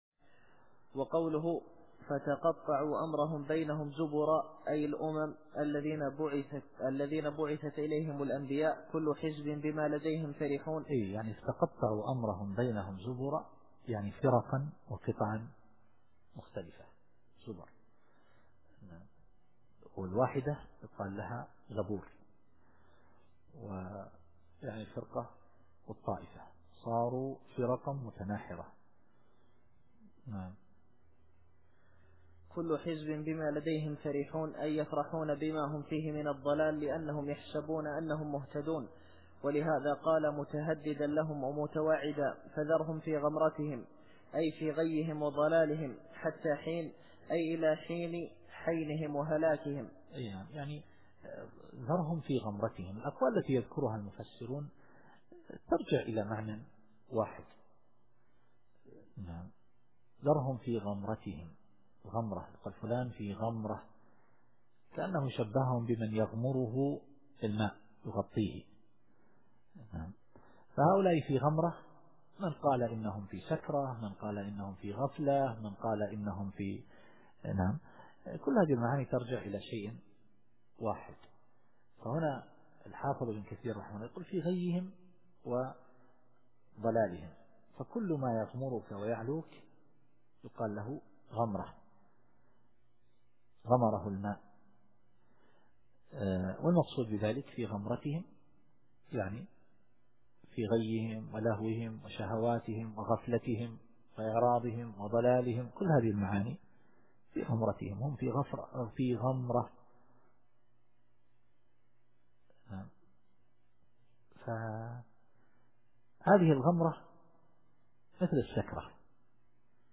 التفسير الصوتي [المؤمنون / 54]